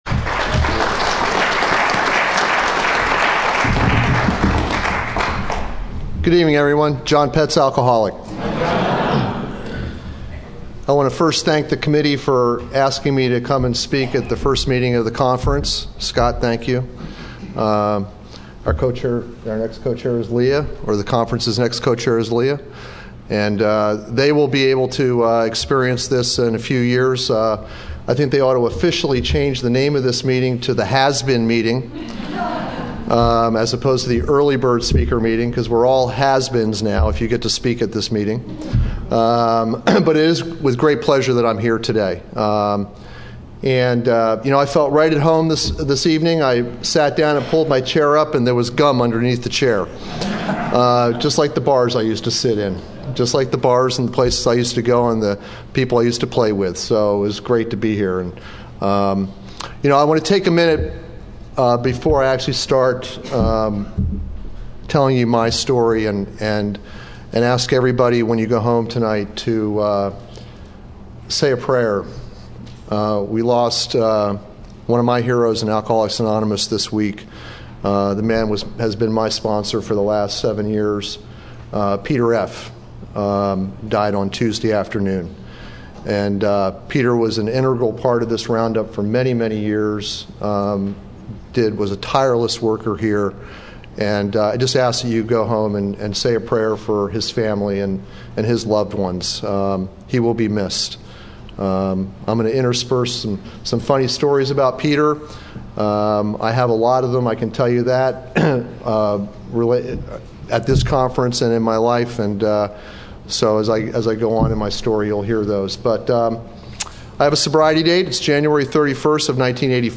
San Diego Spring Roundup 2009